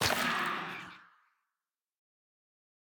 Minecraft Version Minecraft Version 1.21.5 Latest Release | Latest Snapshot 1.21.5 / assets / minecraft / sounds / block / sculk_shrieker / break1.ogg Compare With Compare With Latest Release | Latest Snapshot
break1.ogg